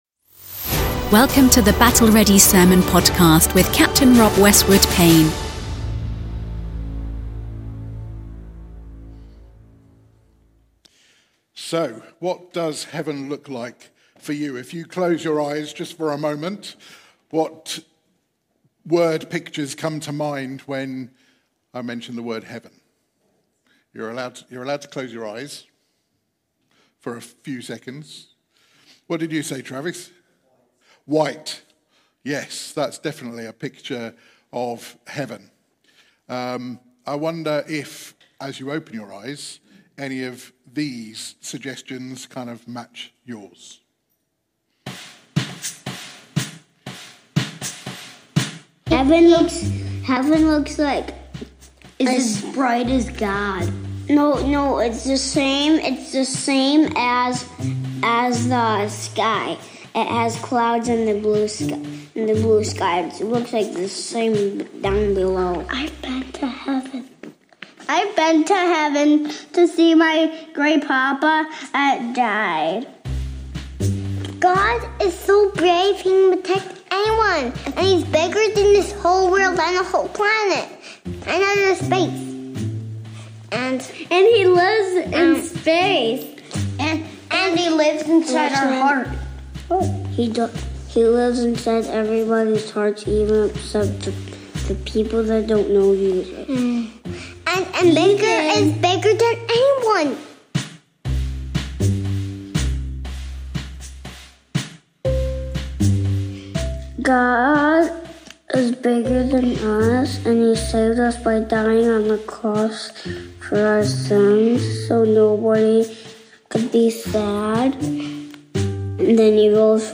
Battle Ready Sermons Revealing Hidden Truth: What Does Heaven Look Like ?